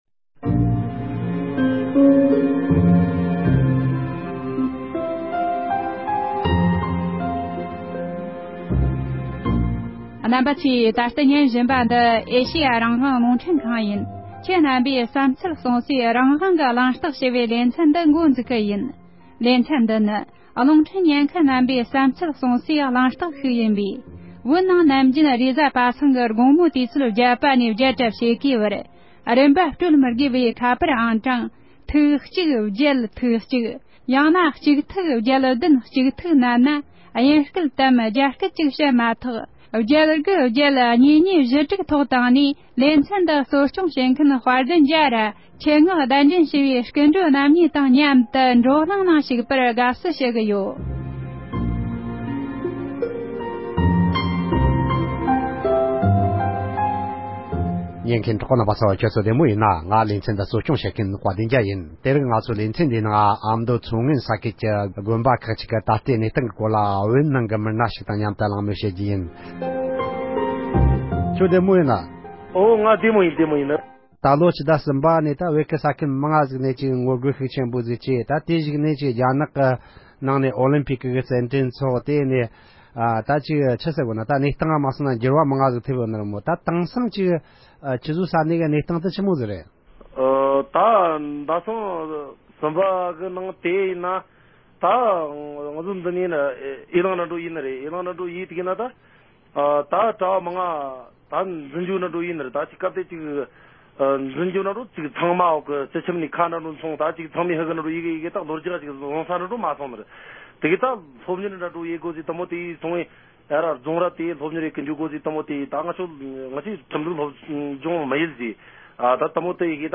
ཨ་མདོ་ནས་ཁ་པར་བརྒྱུད་